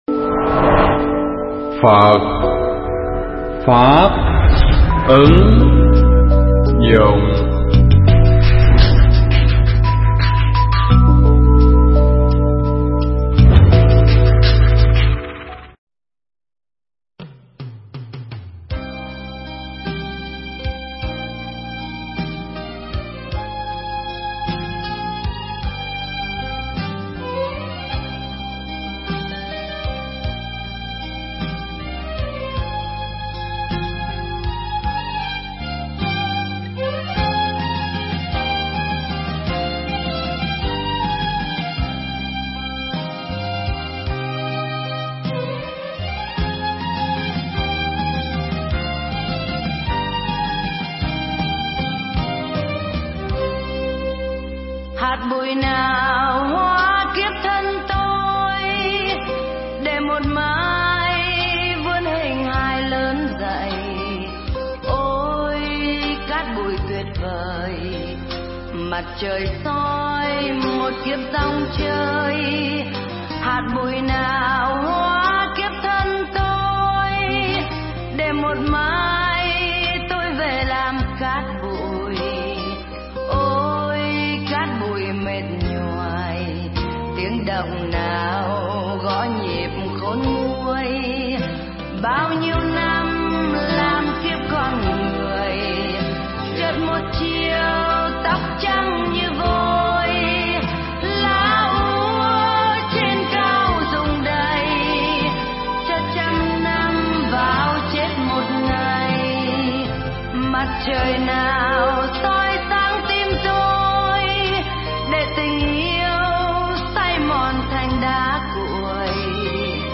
Thuyết pháp Sau Khi Chết Chúng Ta Còn Lại Gì English Subtitles